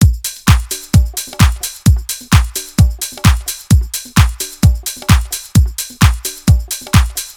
Downtown House
Drum Loops 130bpm